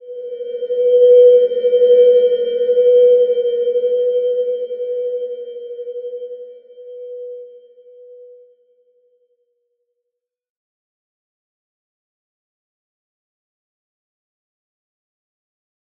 Slow-Distant-Chime-B4-mf.wav